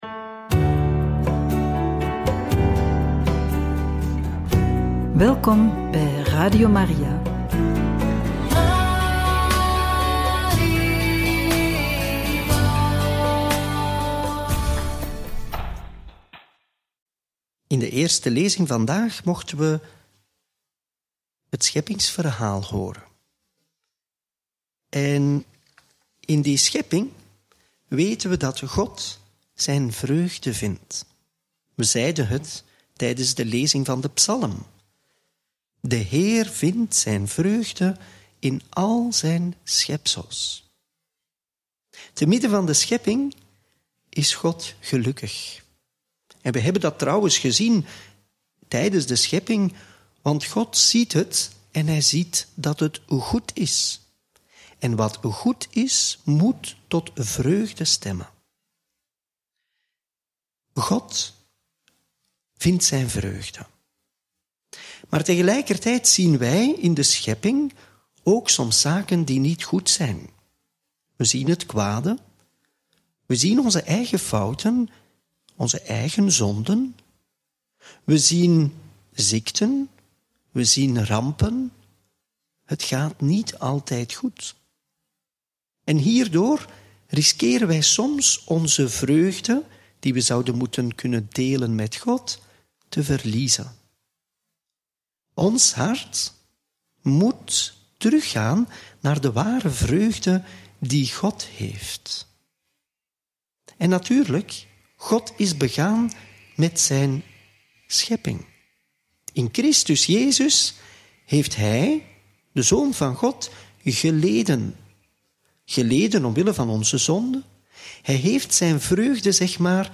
Homilie bij het Evangelie van maandag 10 februari 2025 – Mc 6, 53-56